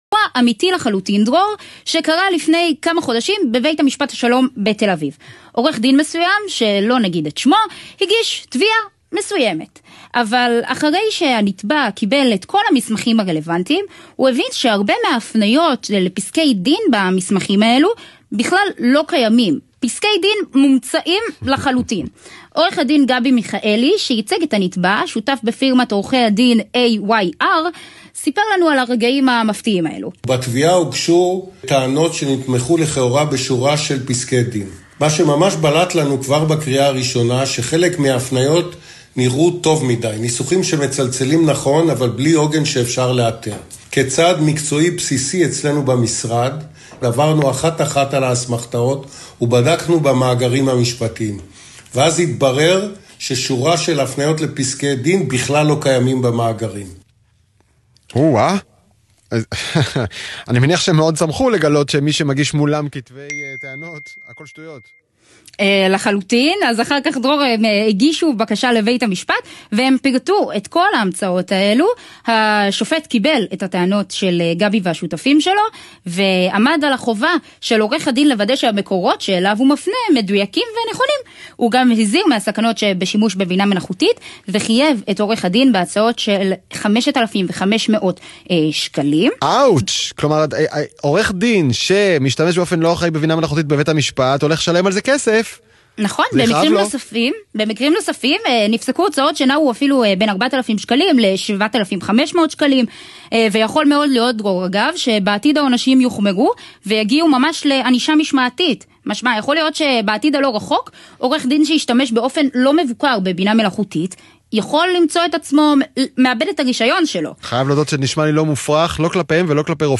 was interviewed on the GALAZ Radio programme “The Future Now”.